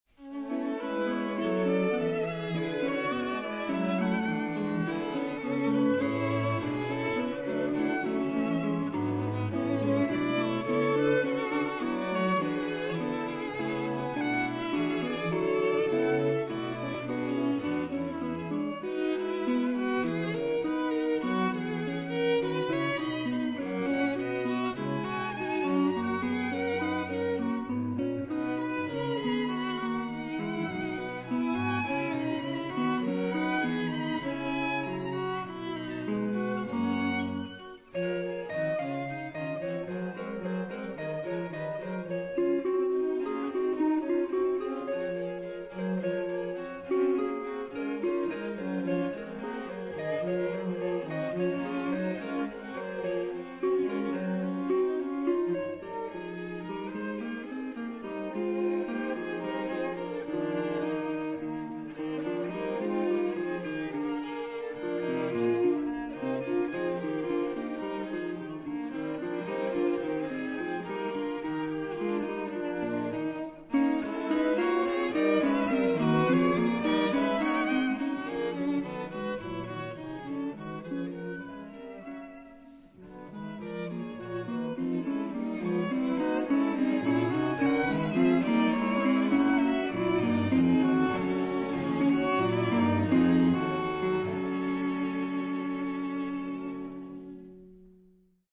2 violins, electric guitar, cello